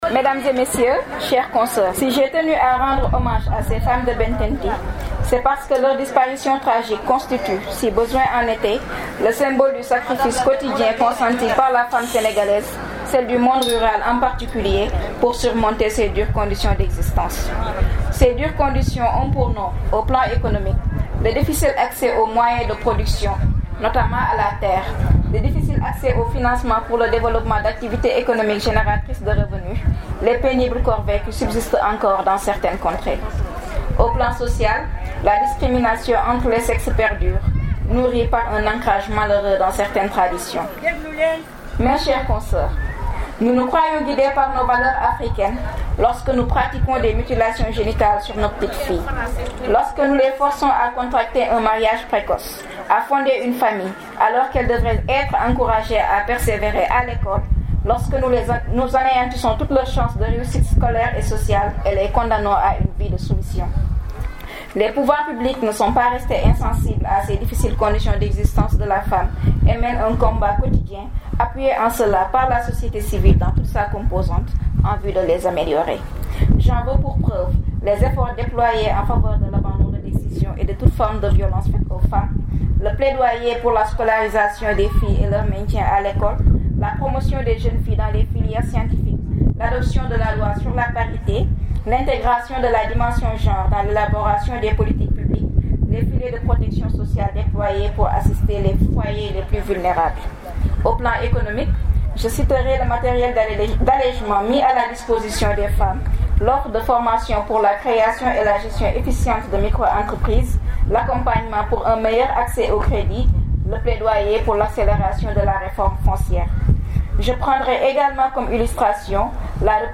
L’édition 2017 de la quinzaine de la femme sénégalaise a été lancée à Koussanar pour les femmes de la région